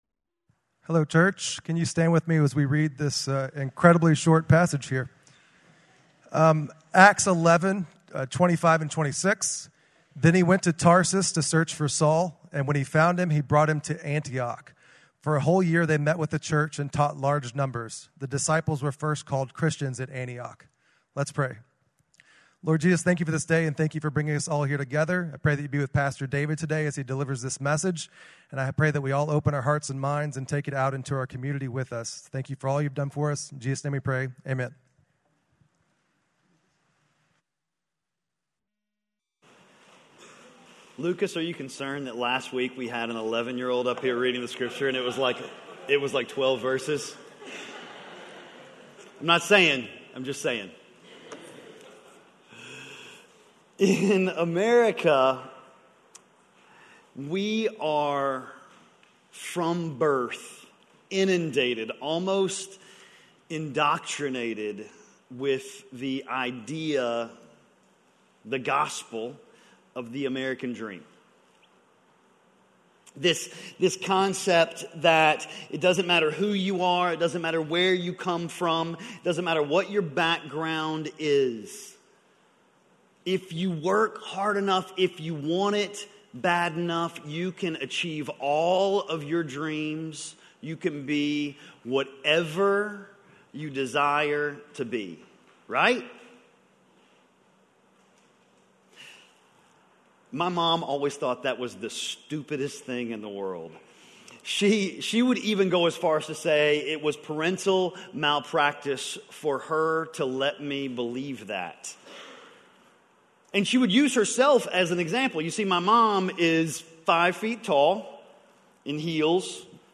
Just Like Barnabas: Leading - Sermon - Lockeland Springs